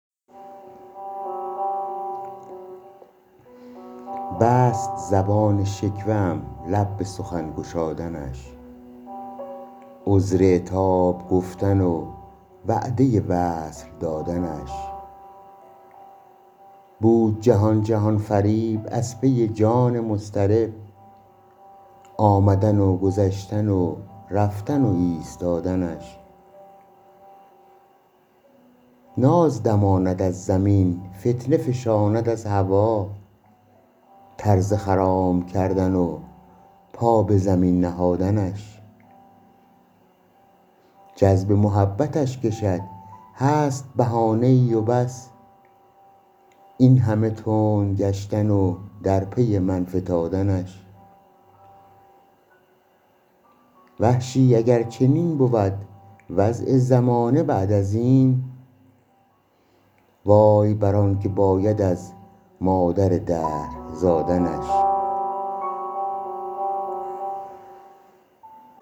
گنجور » نمایش خوانش